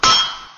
metal.mp3